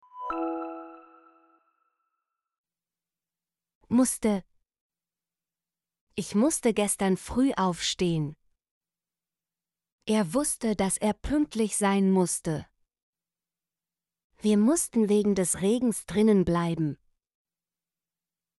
musste - Example Sentences & Pronunciation, German Frequency List